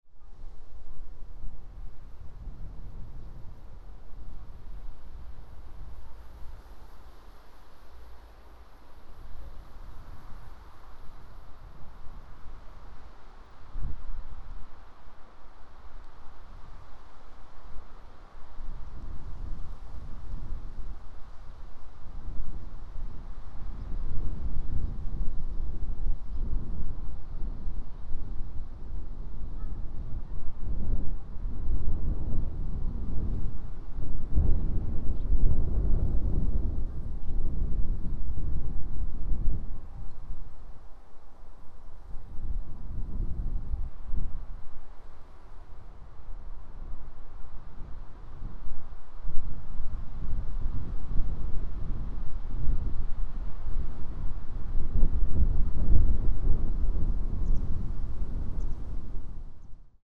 ♦ Some birds were twittering as usual.